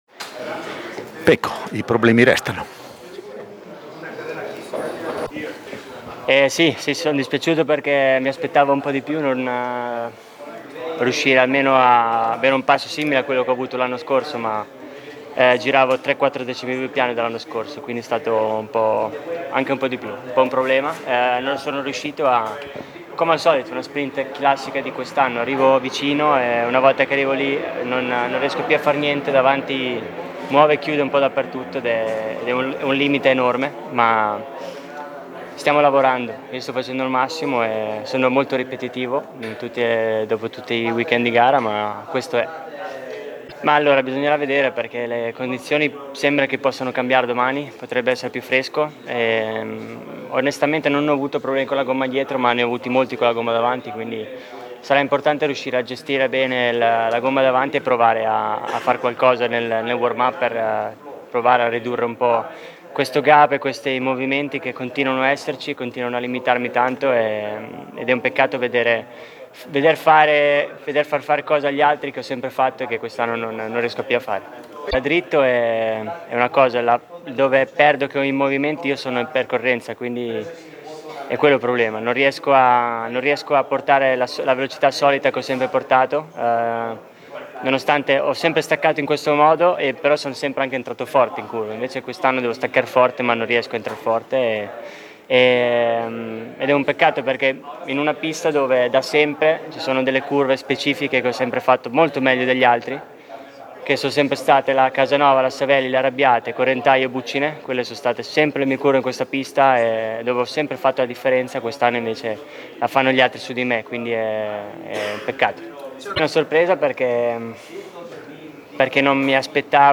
Pecco Bagnaia intervistato